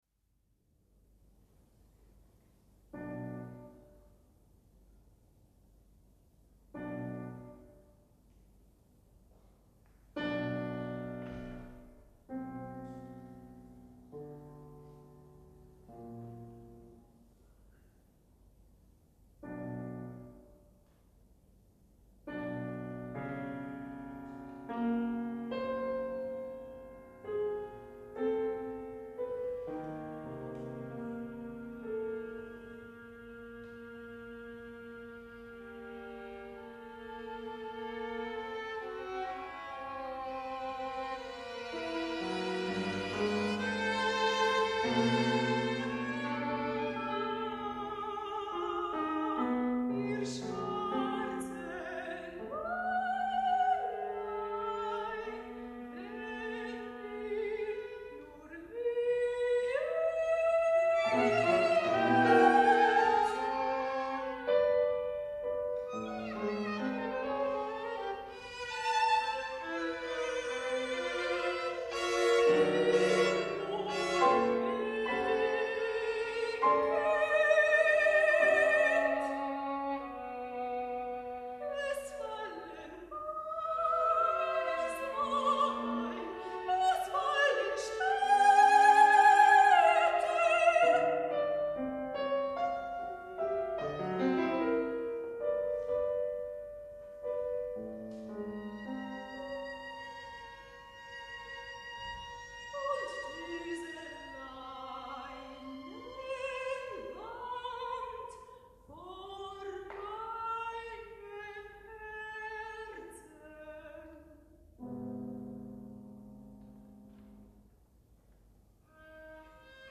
Quartet for
soprano, violin, viola and piano